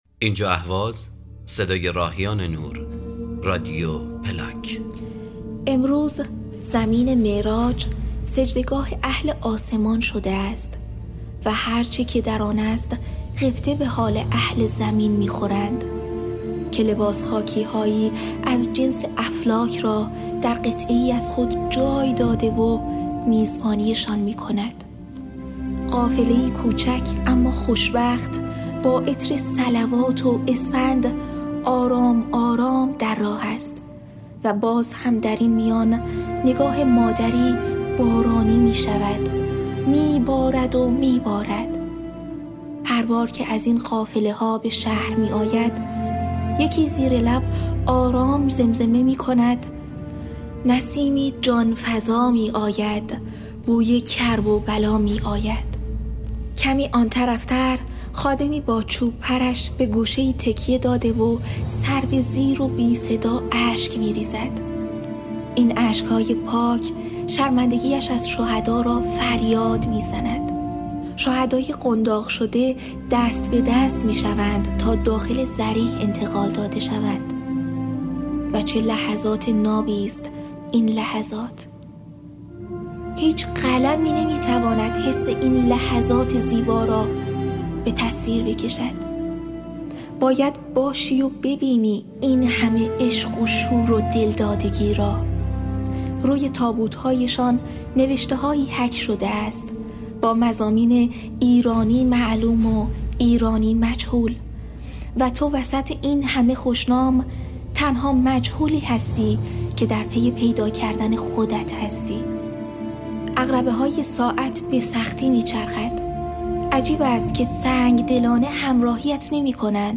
صوت روایتگری